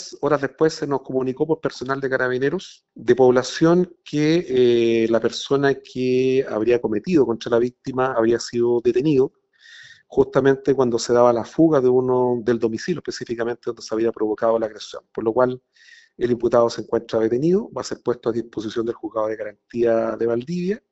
fiscal-ataque-machete.mp3